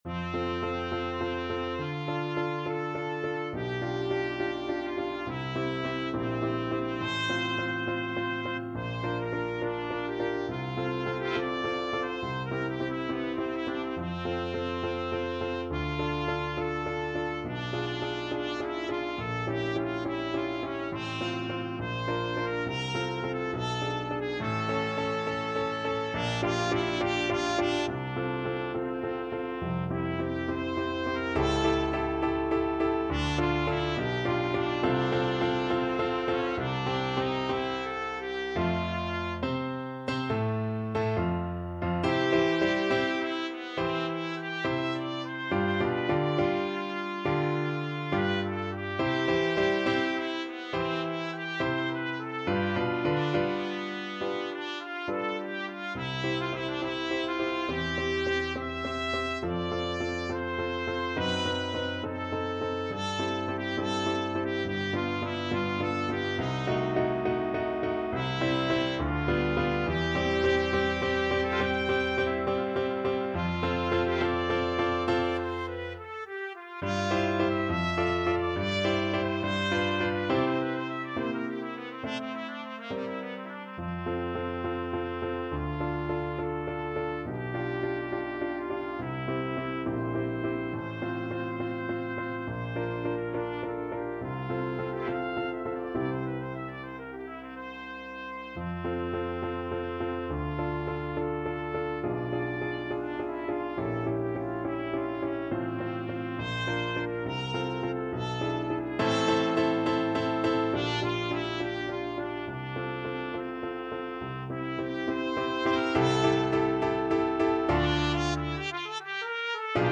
Classical Paganini, Niccolò Cantabile Op.17 Trumpet version
Trumpet
F major (Sounding Pitch) G major (Trumpet in Bb) (View more F major Music for Trumpet )
4/4 (View more 4/4 Music)
G4-Bb6
Classical (View more Classical Trumpet Music)